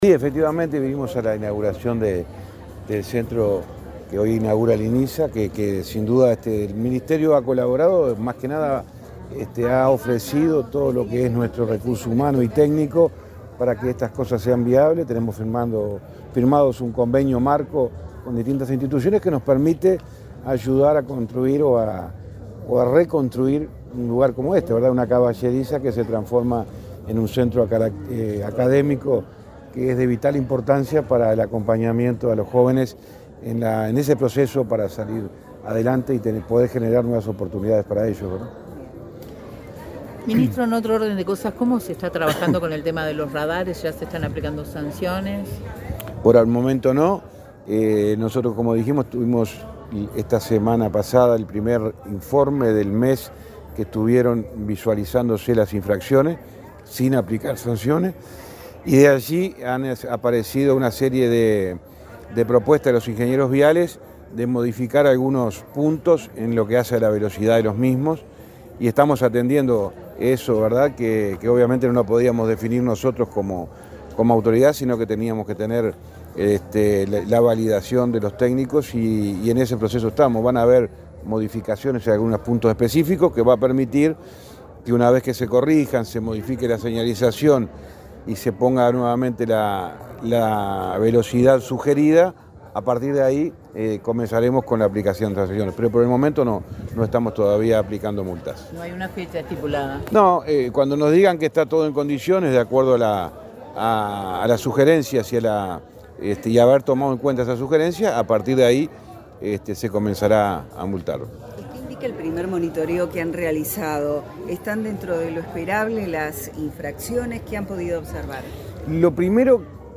Declaraciones del ministro de Transporte, José Luis Falero
Luego dialogó con la prensa.